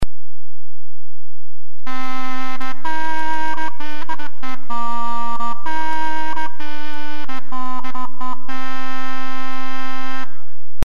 Perhaps designed for indoor use, the stopped end gives the cornamuse a quieter tone than the crumhorn.
Alto cornamuse - MP3
The two sound clips available for download above illustrate the difference in pitch between the two instruments.